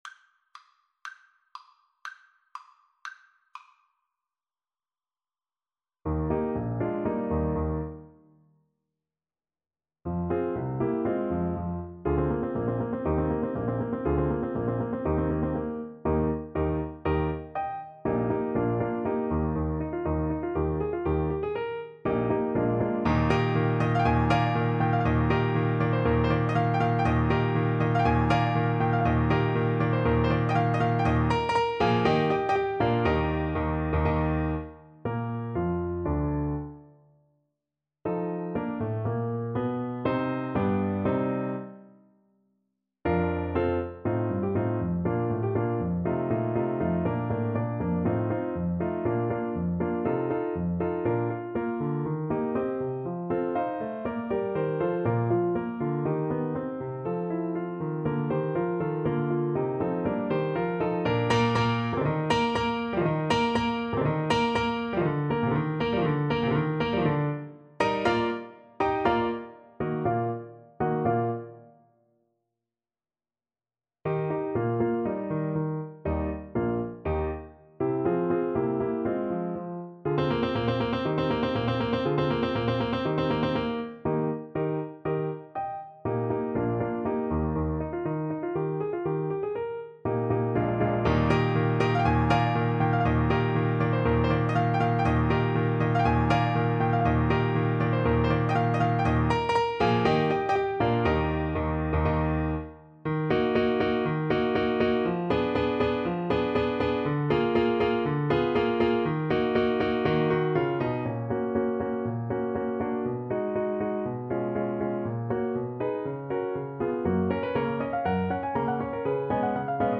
Rondo allegro
2/4 (View more 2/4 Music)
Classical (View more Classical Trumpet Music)